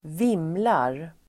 Ladda ner uttalet
Uttal: [²v'im:lar]